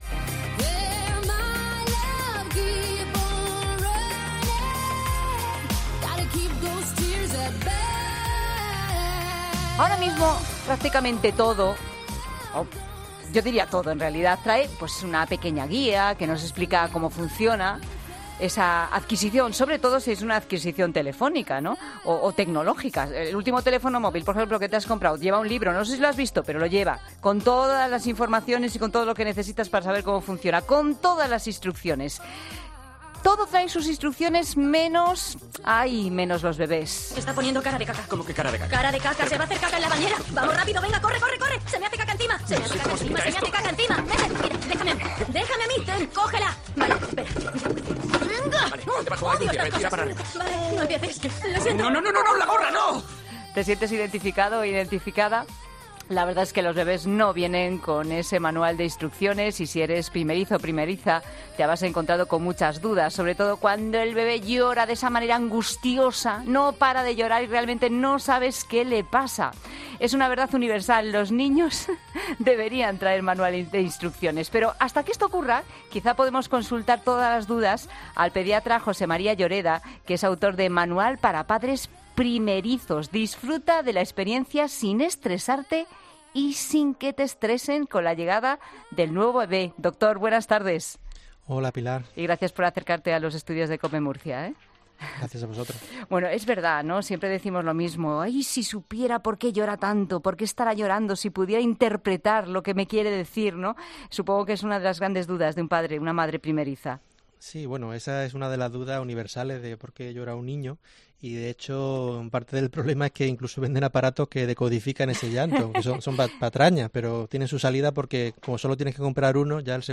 en La Tarde de COPE hemos hablado con el doctor